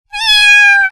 猫の鳴き声-06：可愛い子猫の鳴き声 着信音
猫の鳴き声-06は、可愛らしい子猫が鳴く鳴き声を表現したものです。この鳴き声は、子猫の無邪気さや愛らしさを引き立てる特徴的な鳴き声です。この鳴き声は、高く甘い声で「にゃんにゃん」と連続して鳴く音を表現しています。